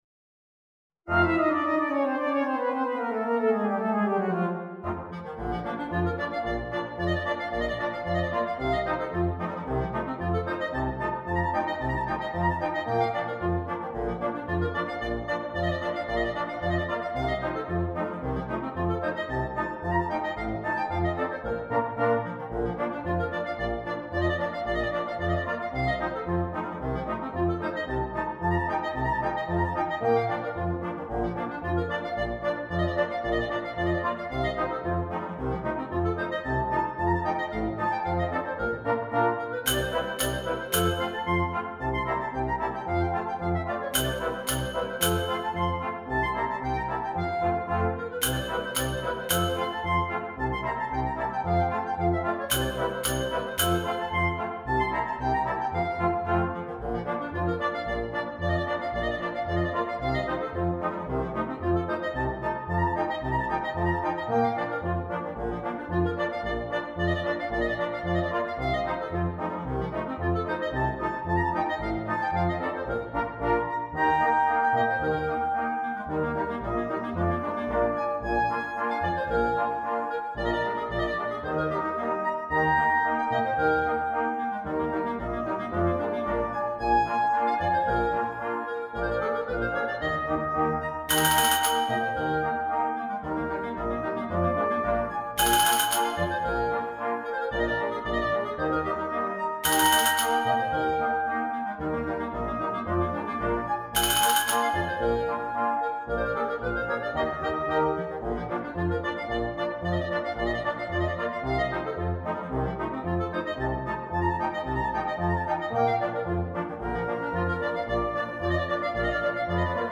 traditional Polish work